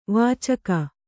♪ vācaka